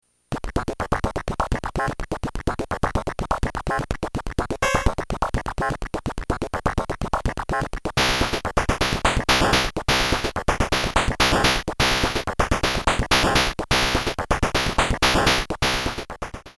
BPM125.5なんだよ。